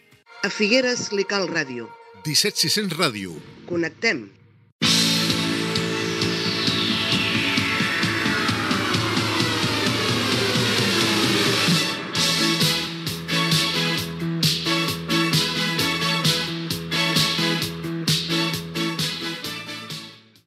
Indicatiu i tema musical